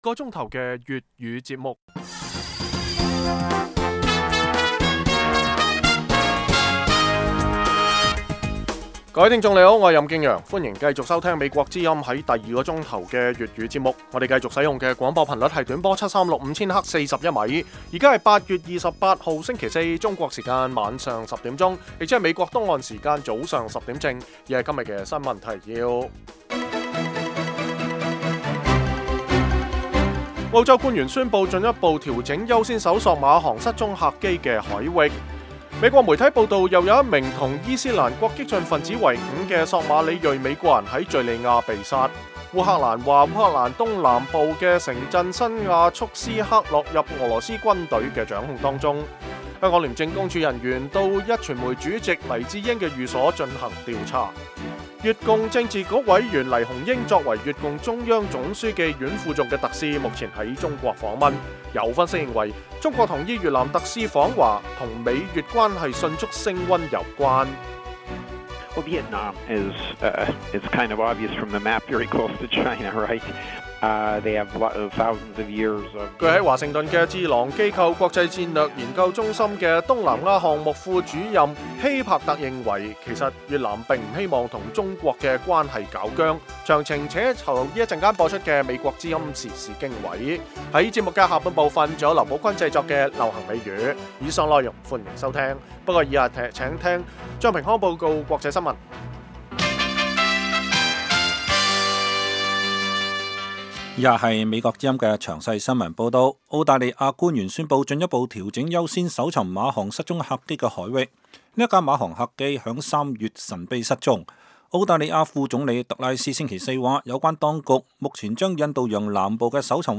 每晚 10點至11點 (1300-1400 UTC)粵語廣播，內容包括簡要新聞、記者報導和簡短專題。